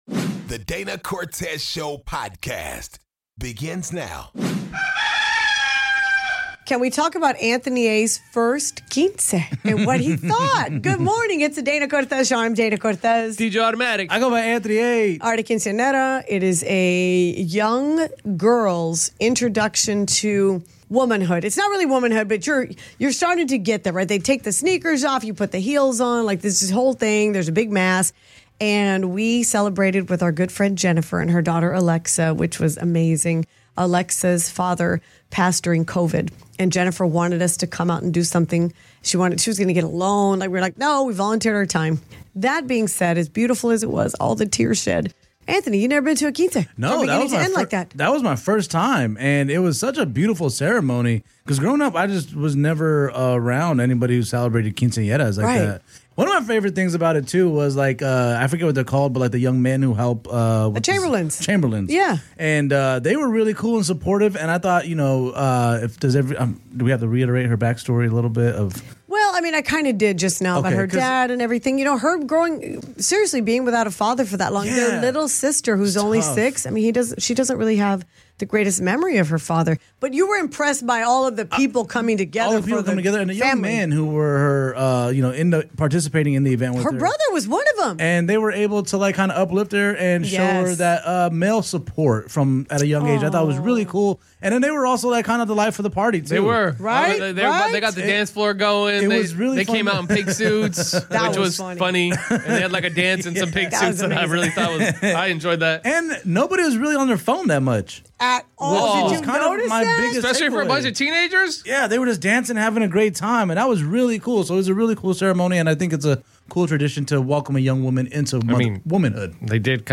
DCS brings on a listener who has been married for 44 years and gives us some gems on how to keep the flame going.